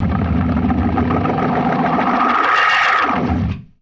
GLISS 6.wav